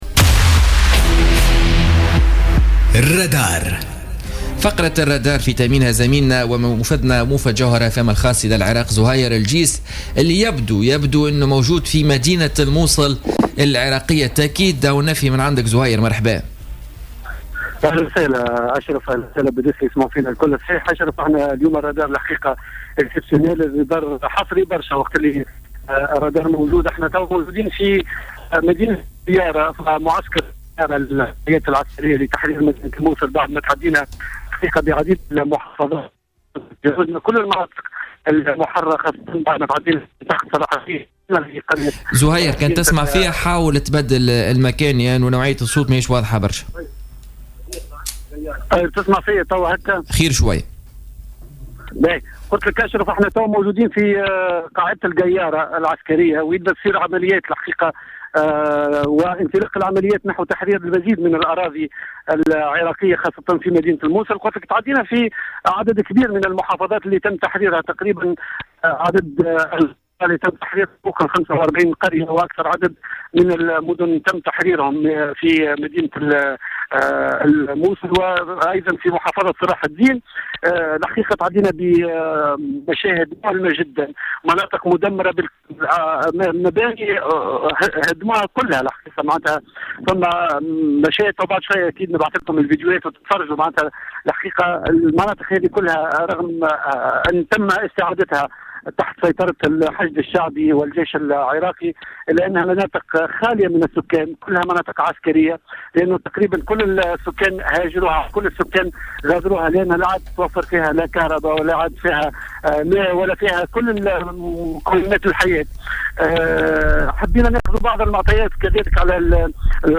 حط الرادار اليوم الإثنين 24 اكتوبر 2016 الرحال في معسكر القيارة أحد جبهات القتال لتحرير الموصل من تنظيم داعش الإرهابي.